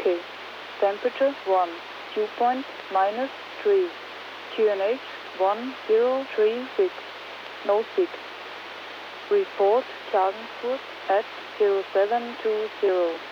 Ein Grossteil des Flugfunks im Nahbereich findet im VHF-Band zwischen 110MHz und 150 MHz statt und wird meist amplitudenmoduliert gesendet.
Der von München nächste Flugwettersender ist VOLMET Innsbruck dessen Sender auf der Zugspitze installiert ist und deshalb gut empfangen werden kann.
volmet.wav